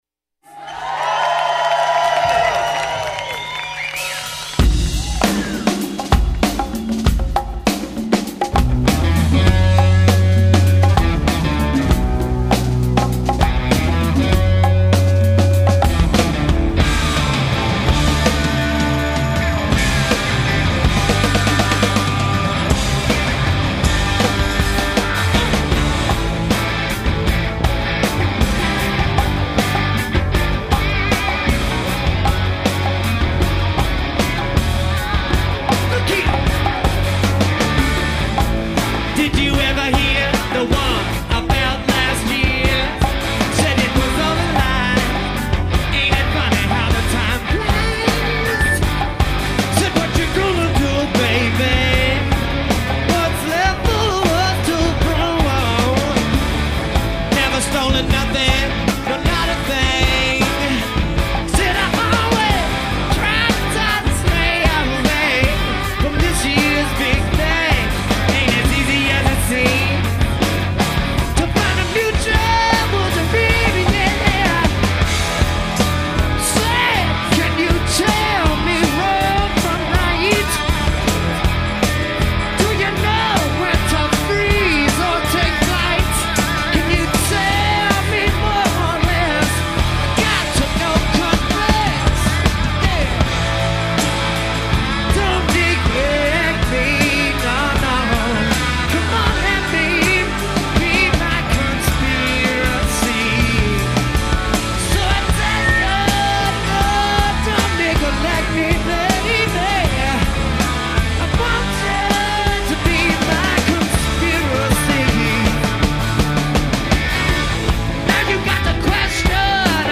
lead singer
no overdubs, in front of a live audience
rocker